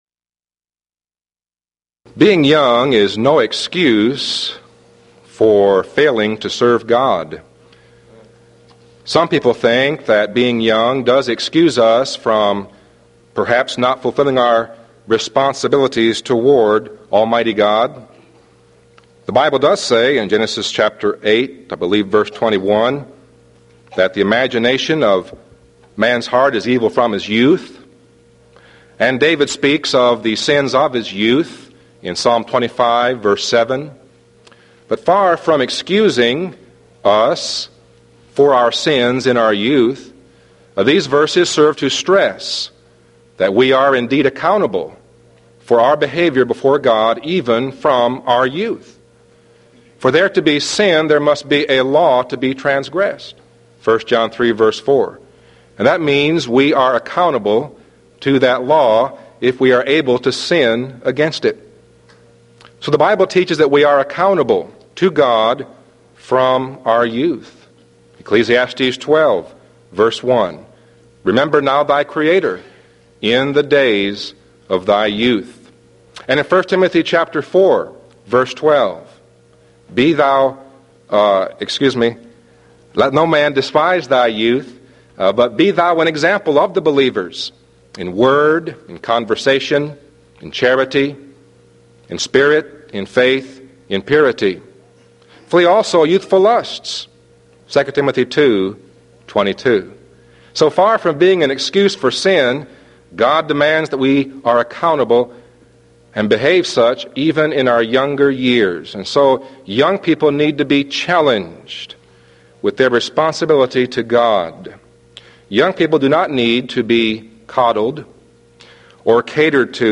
Event: 1993 Mid-West Lectures Theme/Title: The Christian Family